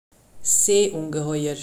uttale); Seeungetüm (uttale)